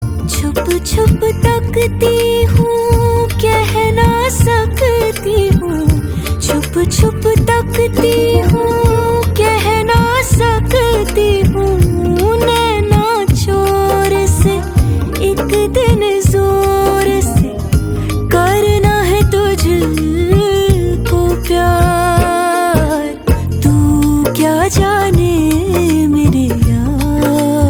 Sung with heartfelt emotion